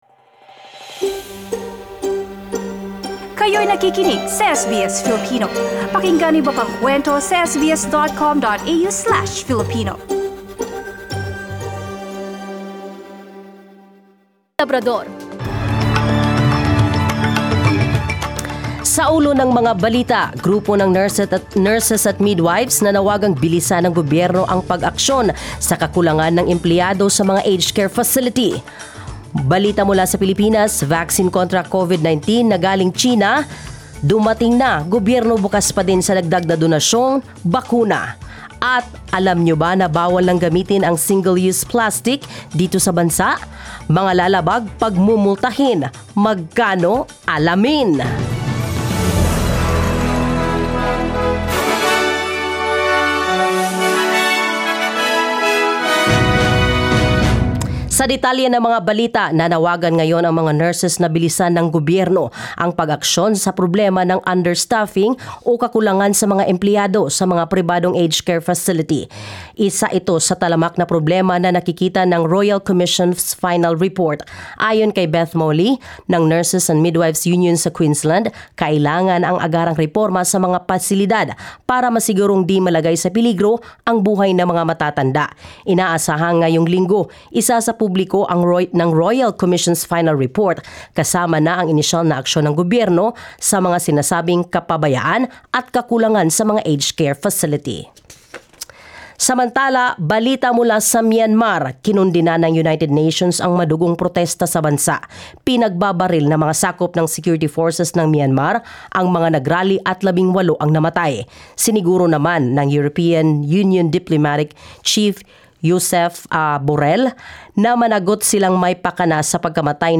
SBS News in Filipino, Monday 1 March